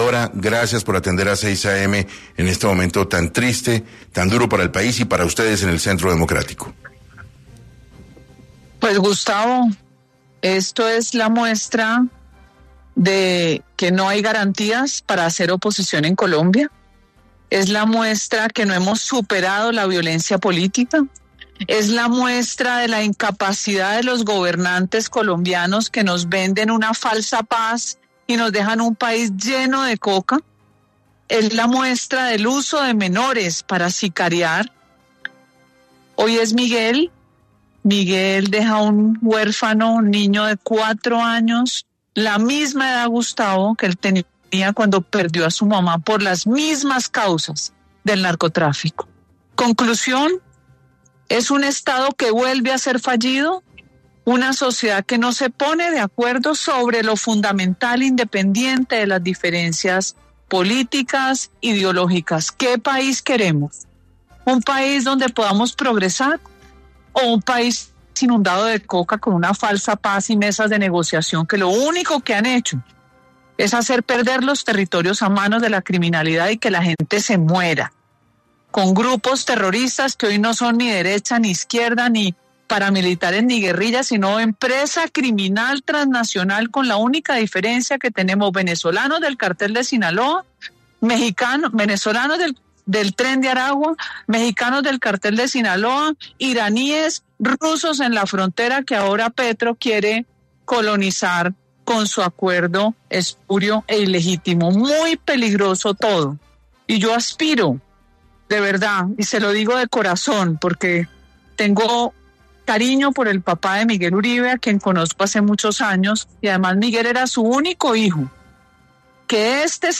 Una de las líderes del partido, María Fernanda Cabal habló en 6AM y expresó que todo hace parte de una paz total que no existe.